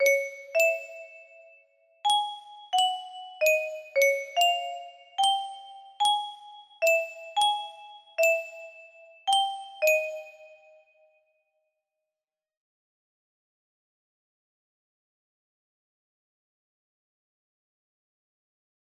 WALTZ OF LOVE - VHG music box melody